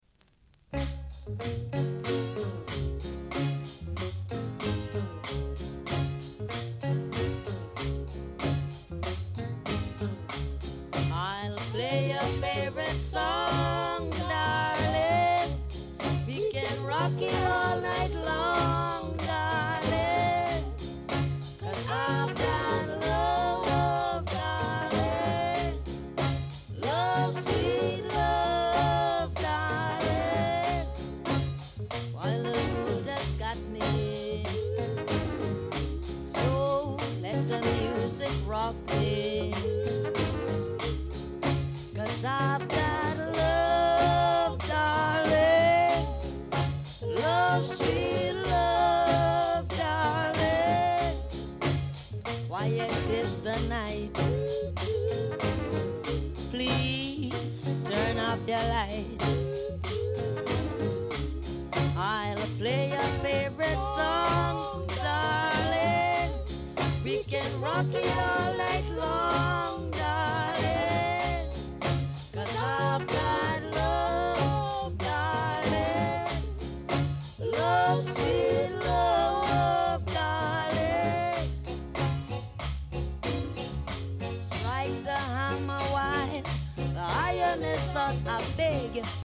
R. Steady Vocal Group
Very rare! great rock steady vocal w-sider!